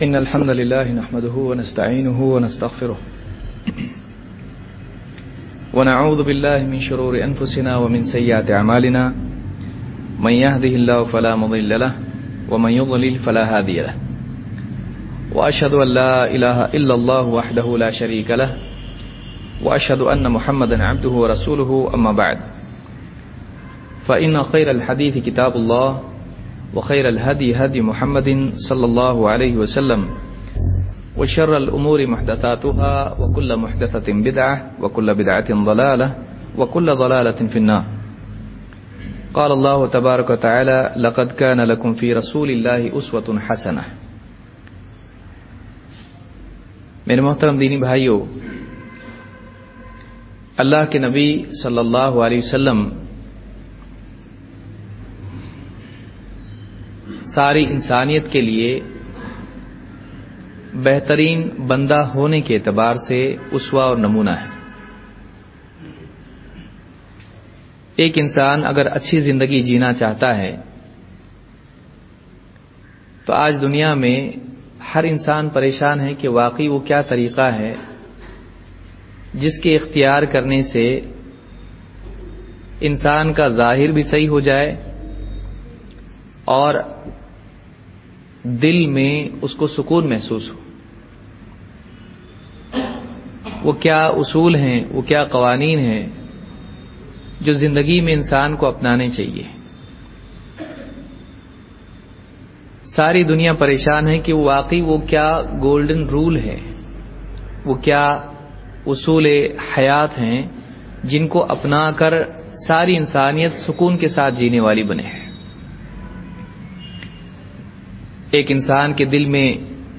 Duroos